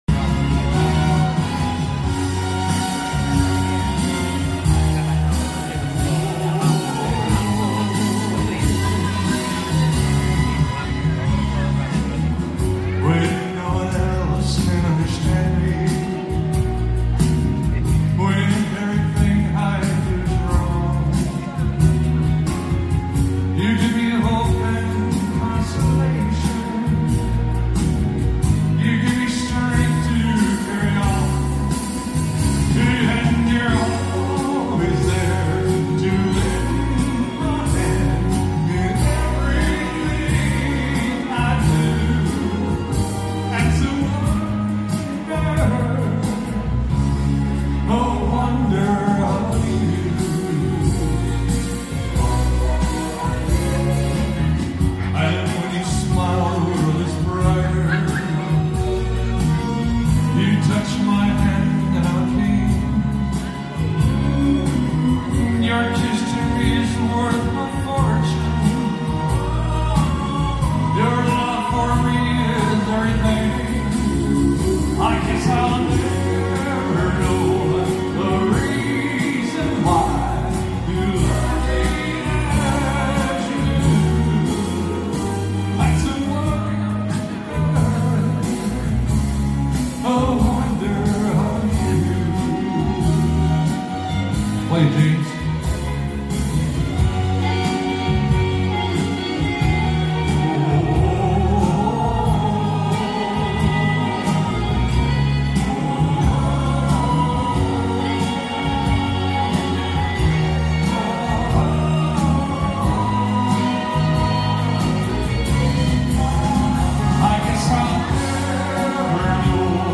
elvis impersonator